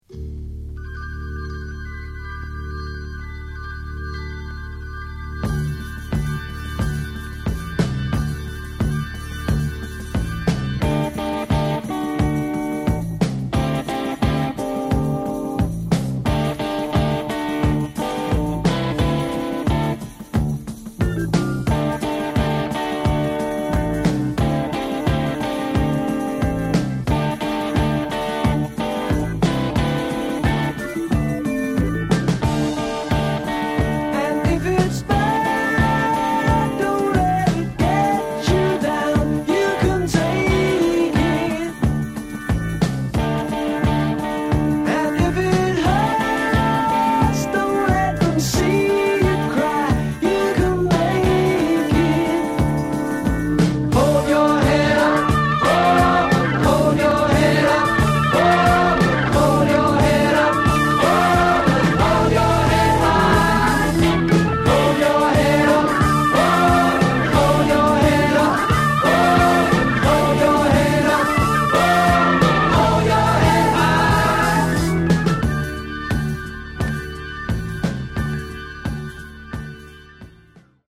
Genre: Hard Rock/Metal